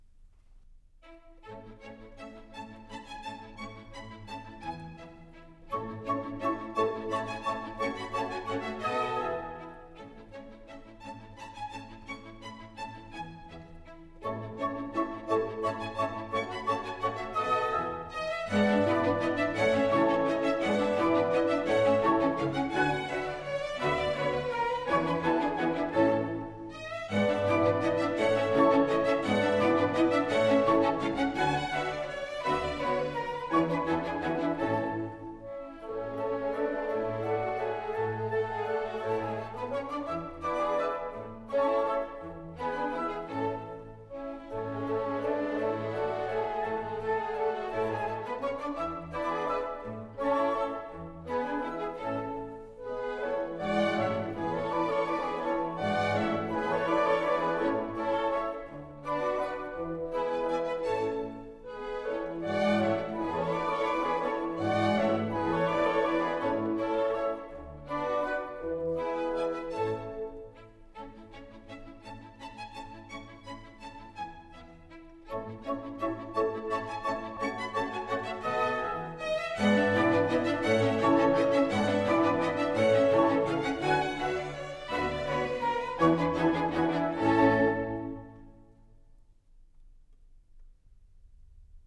Для хорошего настроения - веселый танец.